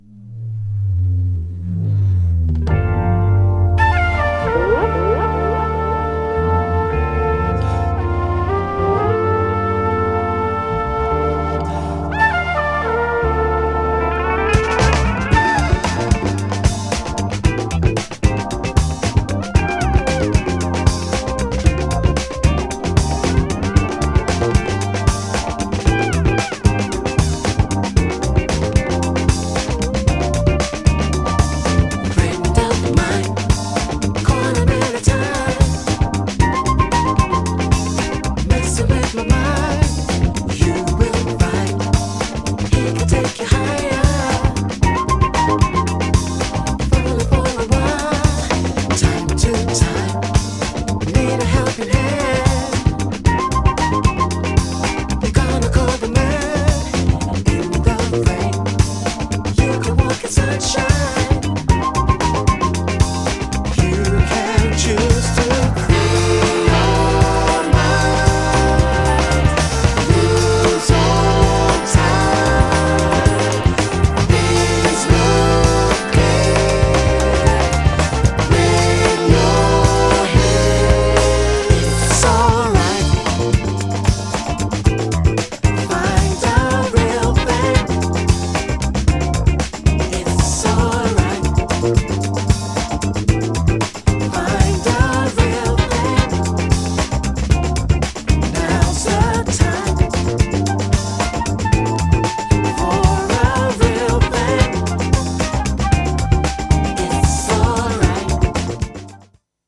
GENRE R&B
BPM 106〜110BPM